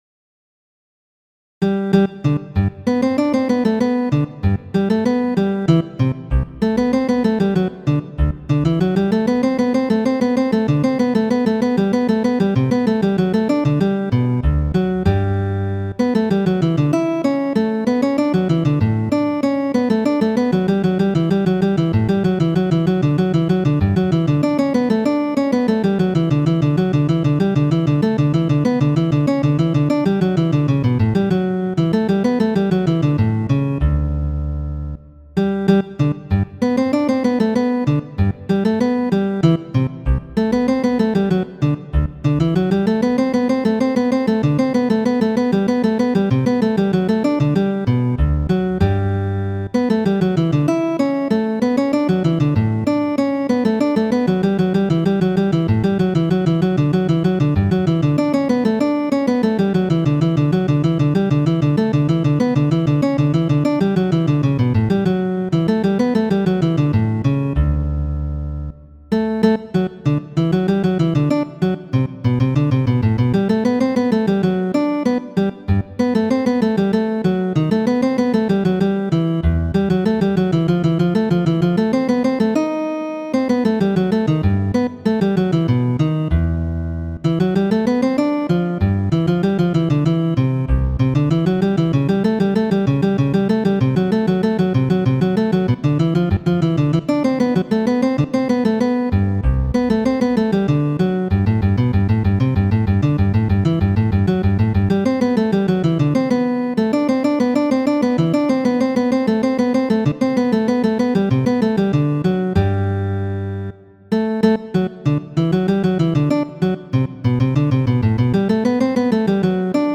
Kajero 49 ~ Kajeroj Muziko : Courante de la Unua Sŭito por ĉelo, komponita de Johano Sebastjan' Baĥ.